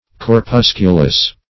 Corpusculous \Cor*pus"cu*lous\ (-k?-l?s), a.
corpusculous.mp3